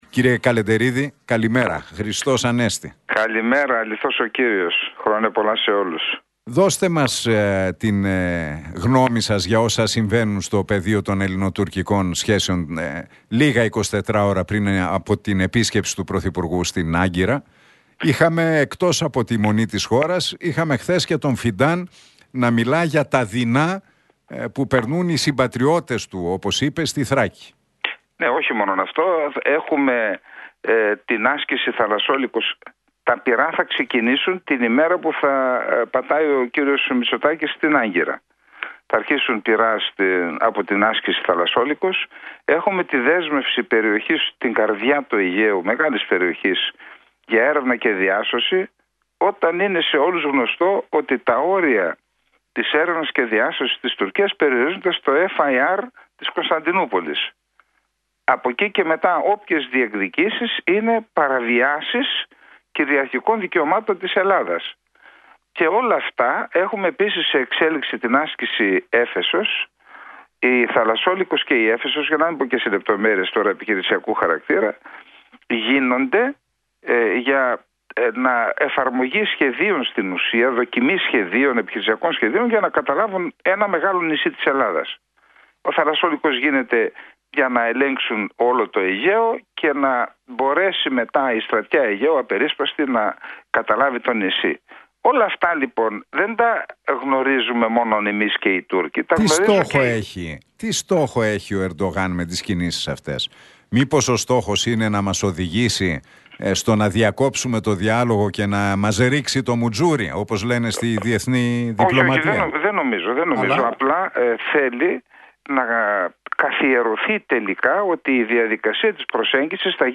Για τις τουρκικές προκλήσεις και τα ελληνοτουρκικά μίλησε στον Realfm 97,8 και την εκπομπή του Νίκου Χατζηνικολάου ο Σάββας Καλεντερίδης.